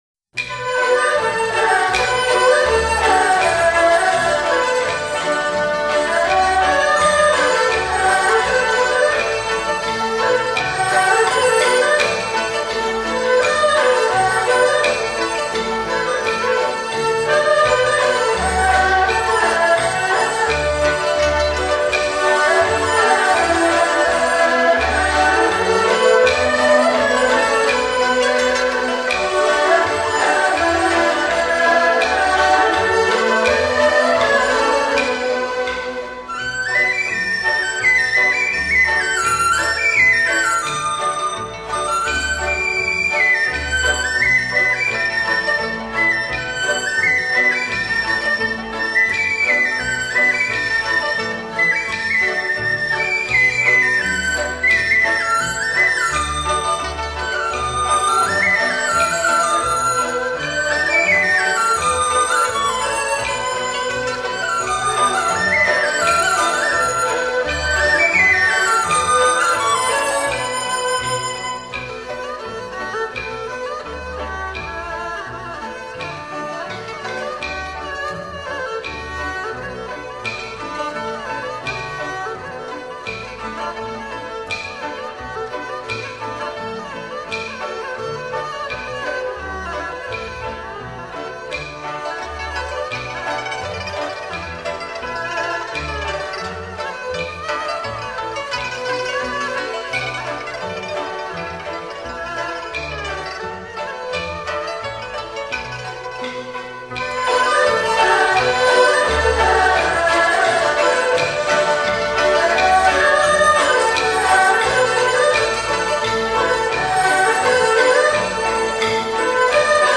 该曲旋律柔美流畅，节奏舒展自如，结构采用民间的起承转合句式，虽然短小，却流传甚广。